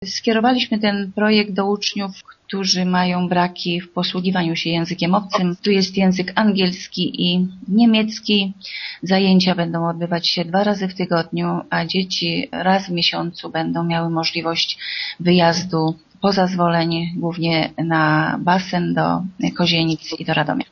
„Propozycja jest skierowana do uczniów pierwszych i drugich klas naszego gimnazjum” – mówi burmistrz Zwolenia Bogusława Jaworska: